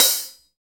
HAT REAL H03.wav